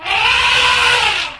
Paths to standard EV3 sounds.
Animal sounds
ELEPHANT_CALL
elephant_call.wav